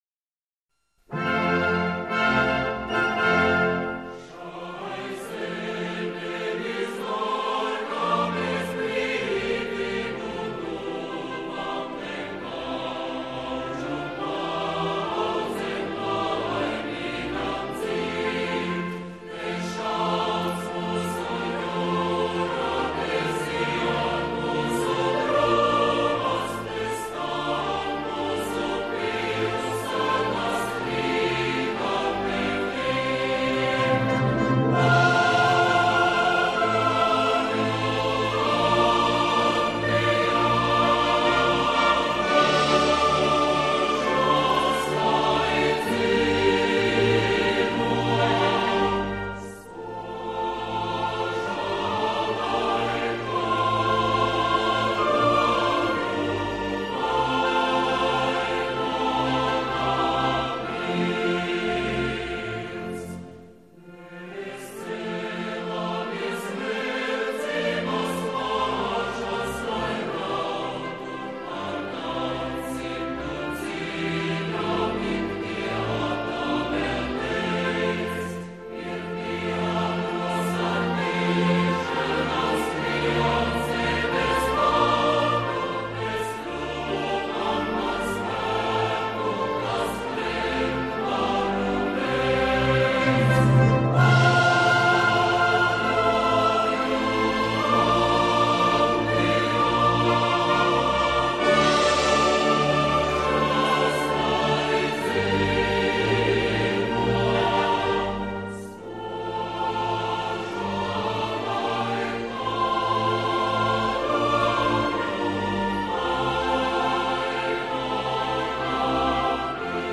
バルト３国のうちの一国、ラトヴィアSSR国歌です。メロディーを聴いて見るとなかなか落ち着いた感じで、一度聴くと耳にこびり付いてなかなか離れません。